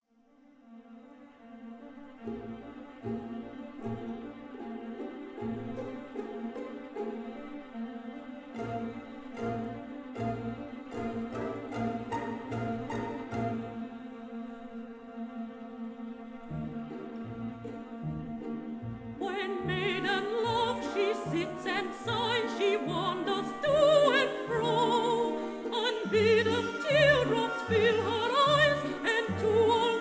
contralto